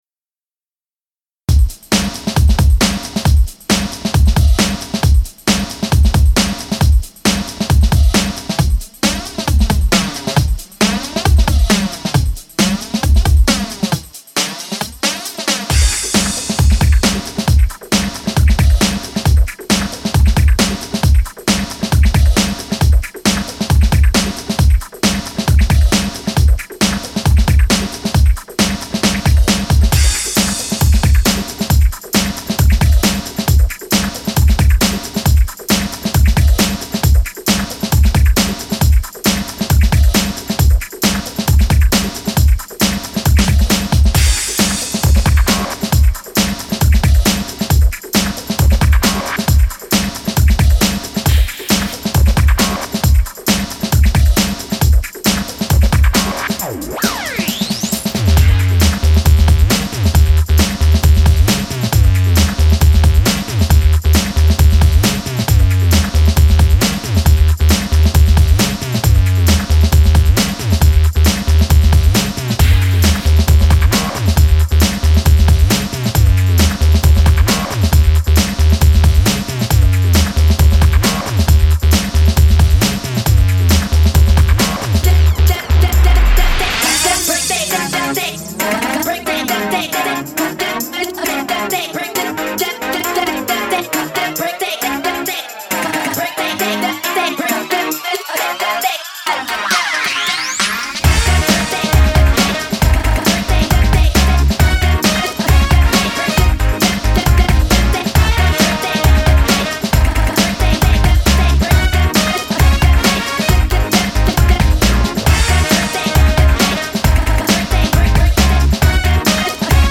Peep my mix: http